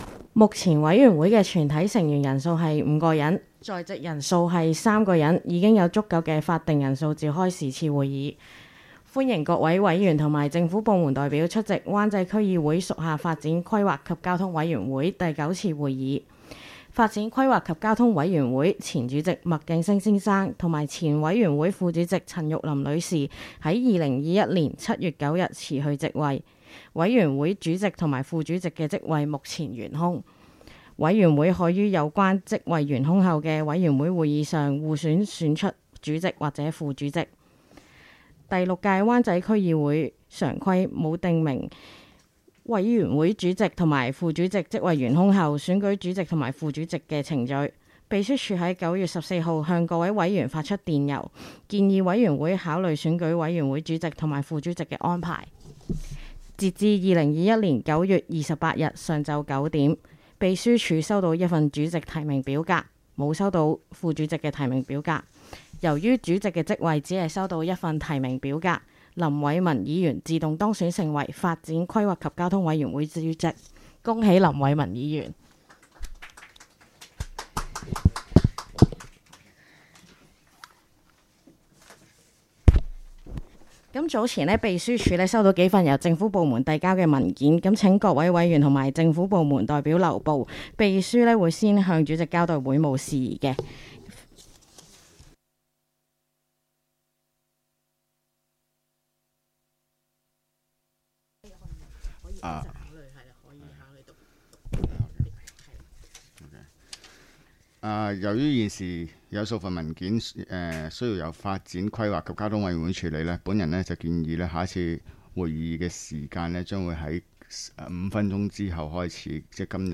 委员会会议的录音记录
地点: 香港湾仔轩尼诗道130号修顿中心21楼 湾仔民政事务处区议会会议室